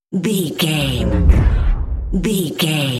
Deep whoosh to hit sci fi
Sound Effects
Atonal
dark
futuristic
intense
tension